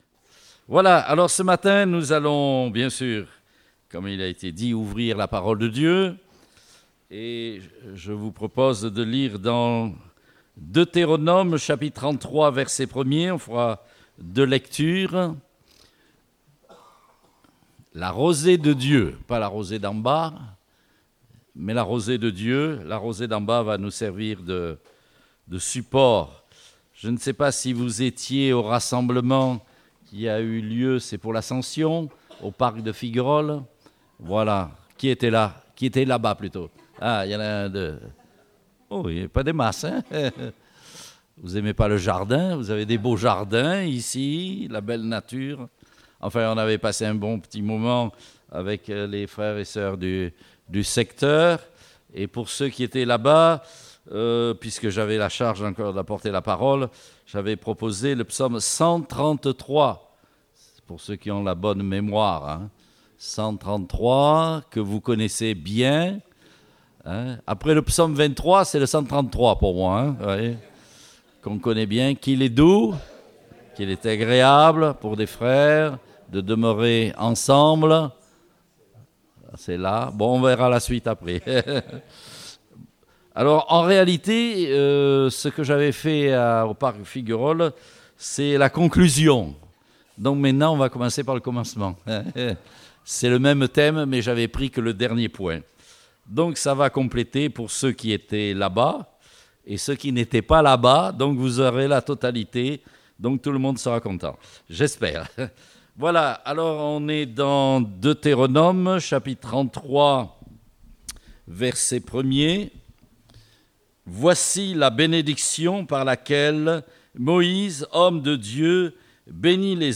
Date : 7 juillet 2019 (Culte Dominical)